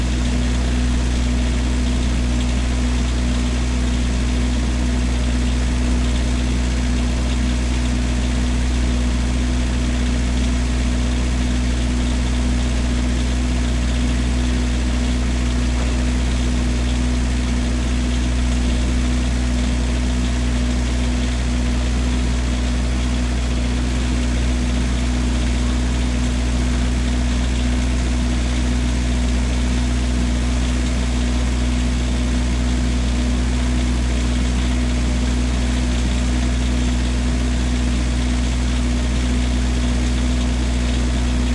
汽车发动机
描述：柴油发动机启动，空转，然后轻轻转动，然后再次关闭。
标签： 启动 点火 柴油发动机 发动机
声道立体声